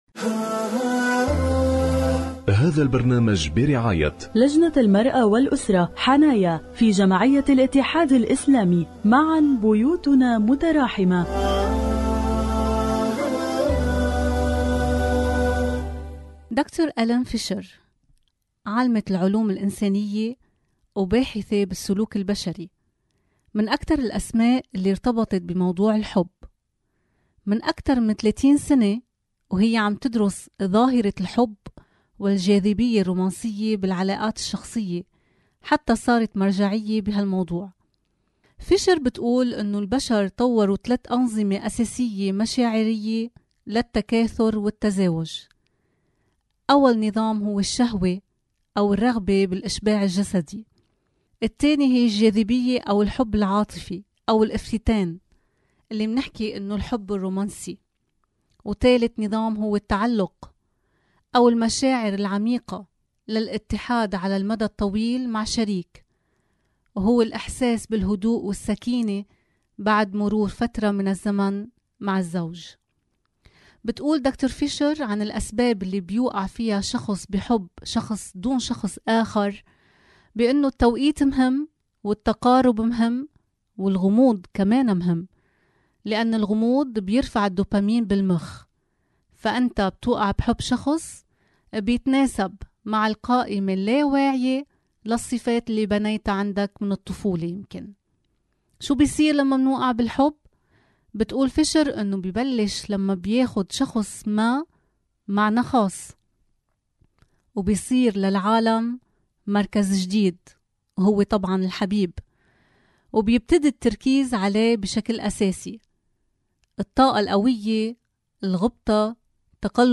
برعاية لجنة المرأة والأسرة-حنايا في جمعية الاتحاد الإسلامي على إذاعة الفجر.